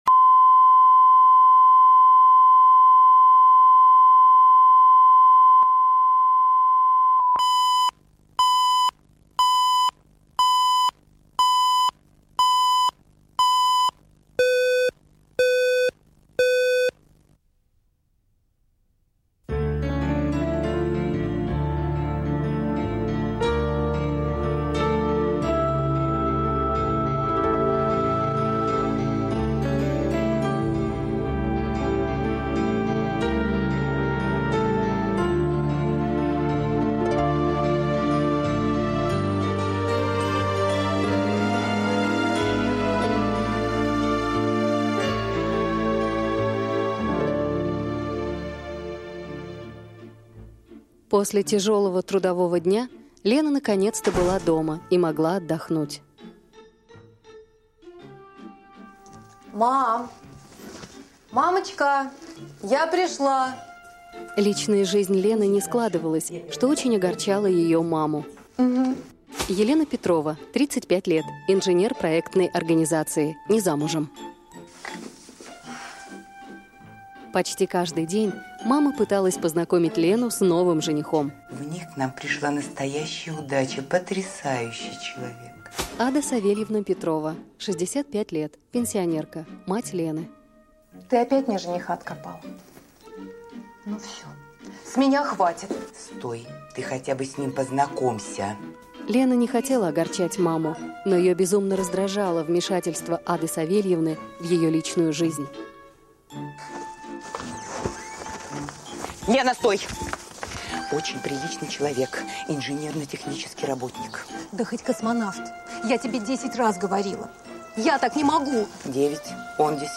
Аудиокнига Десятый